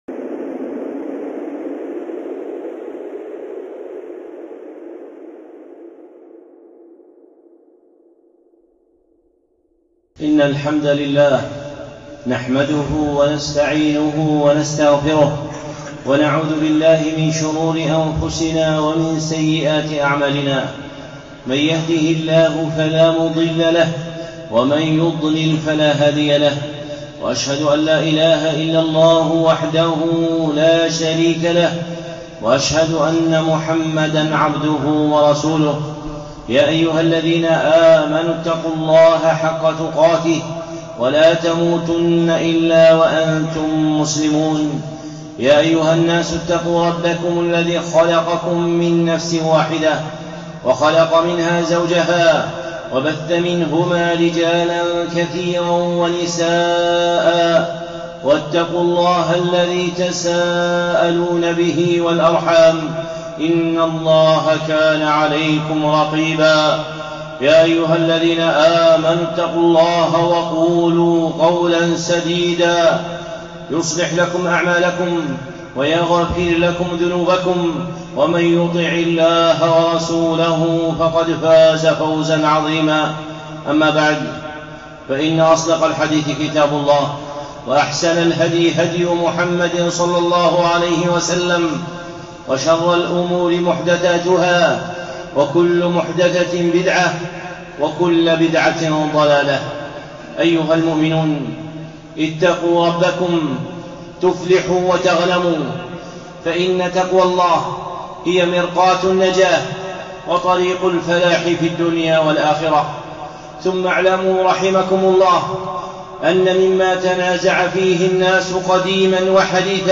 خطبة (أنتم الأعلون) الشيخ صالح العصيمي